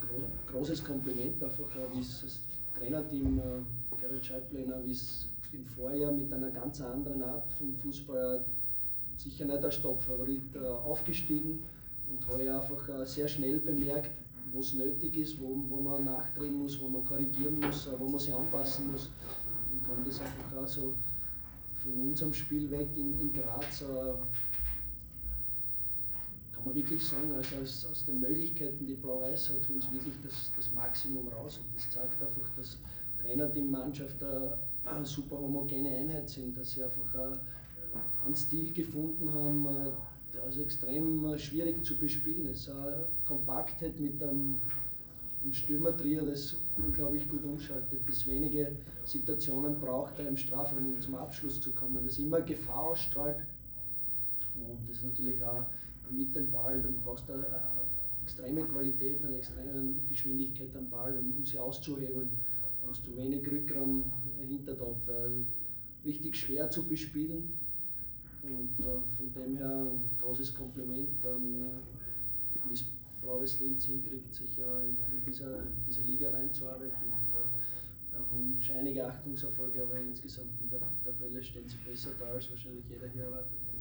Cheftrainer Christian Ilzer bei der Pressekonferenz nach dem Unentschieden in Linz.